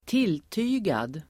Ladda ner uttalet
Uttal: [²t'il:ty:gad]